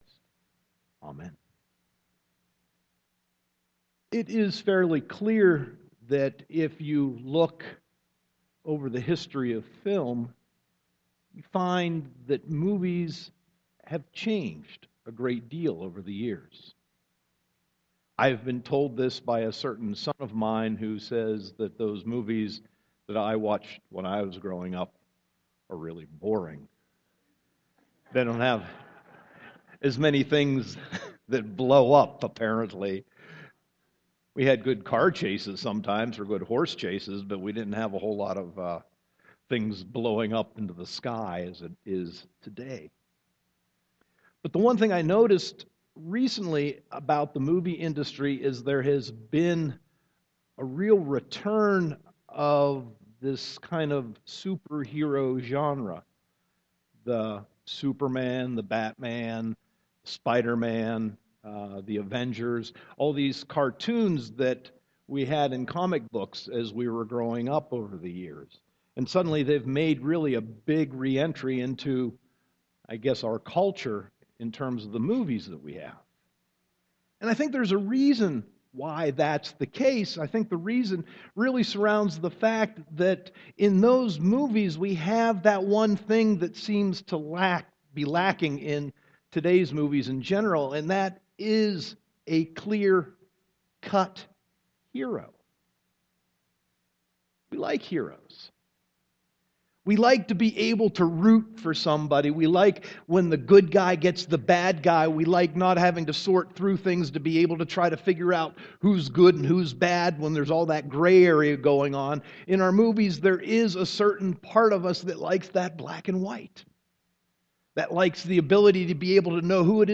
Sermon 9.7.2014